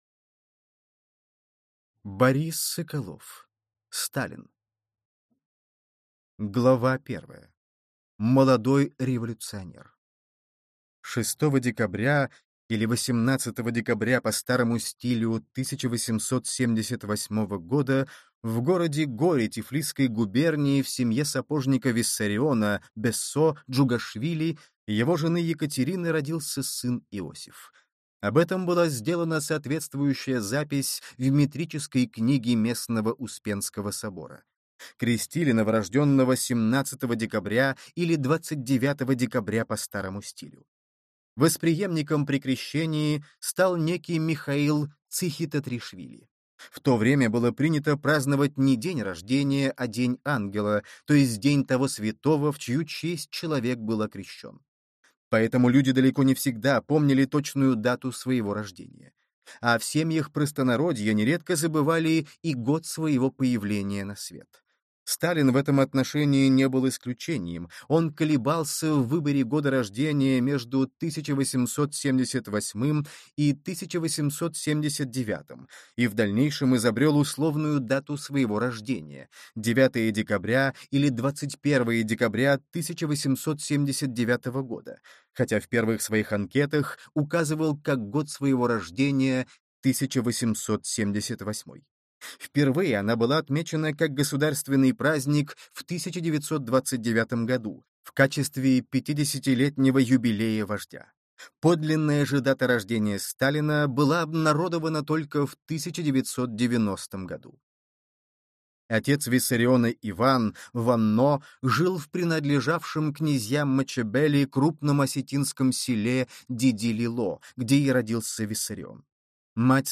Аудиокнига Сталин | Библиотека аудиокниг
Прослушать и бесплатно скачать фрагмент аудиокниги